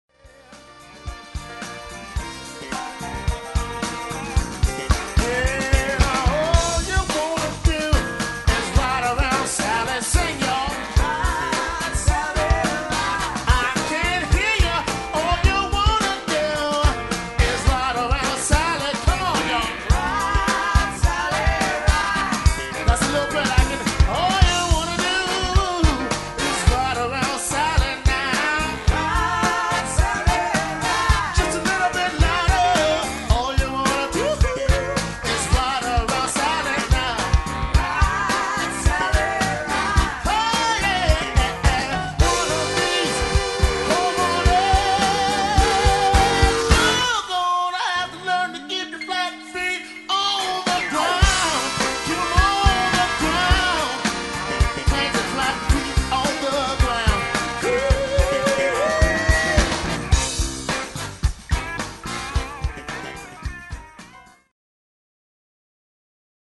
Trombone
El.bass
Div. keyboards
Drums
C. Fem. Male.